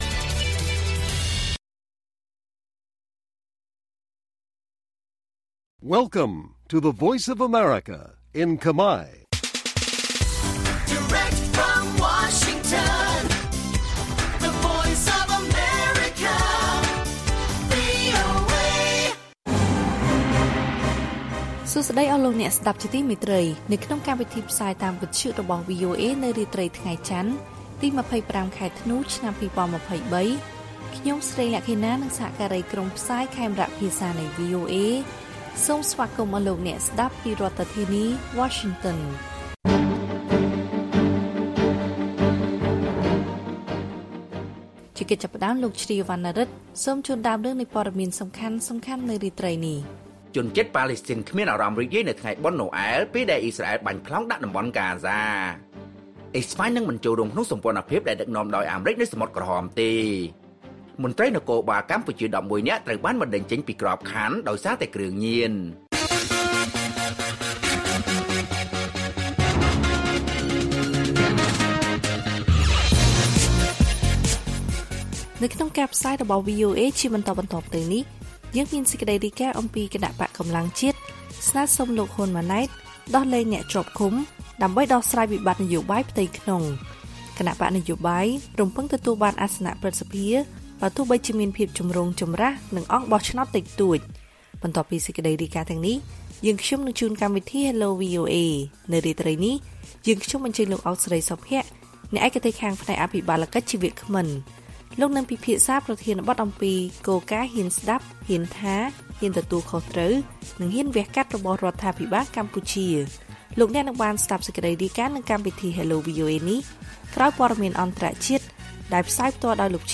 ព័ត៌មានពេលរាត្រី ២៥ ធ្នូ៖ គណបក្សកម្លាំងជាតិស្នើសុំលោក ហ៊ុន ម៉ាណែត ដោះលែងអ្នកជាប់ឃុំ ដើម្បីដោះស្រាយវិបត្តិនយោបាយផ្ទៃក្នុង